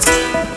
PIANOLOOP1-R.wav